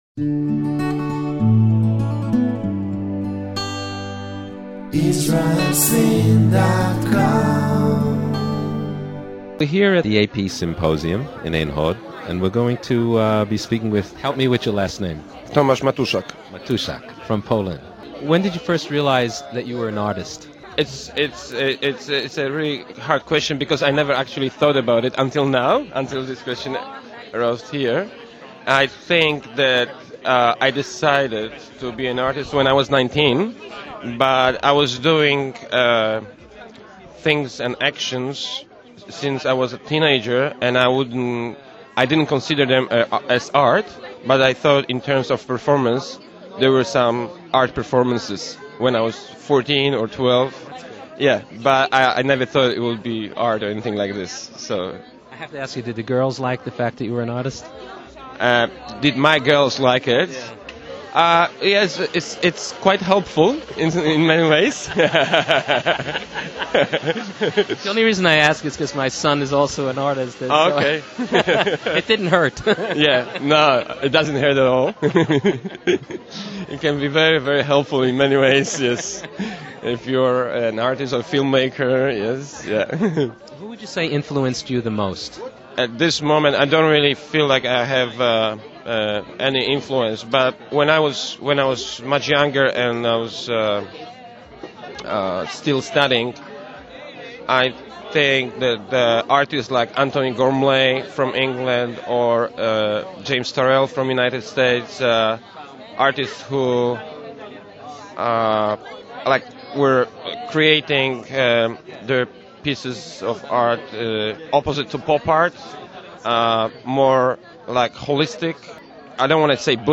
Part II Interviews of Artists at the AP 2007 International Art Symposium in Ein Hod, Israel